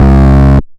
Double Bass (JW3).wav